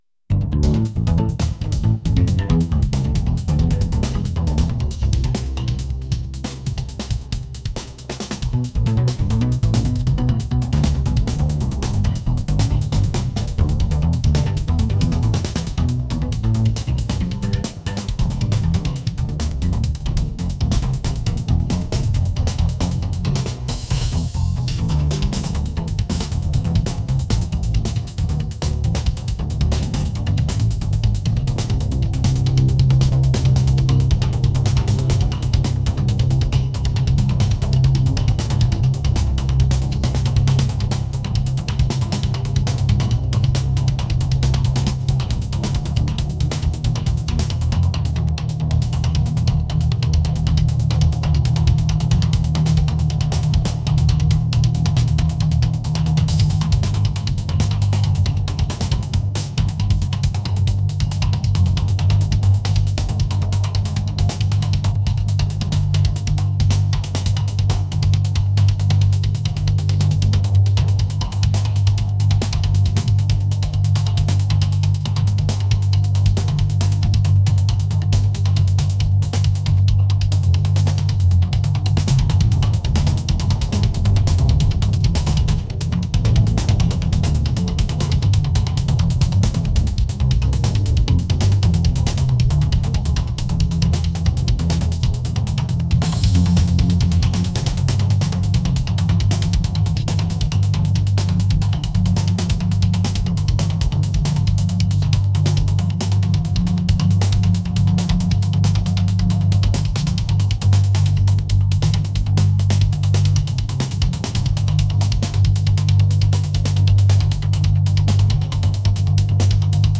energetic | jazz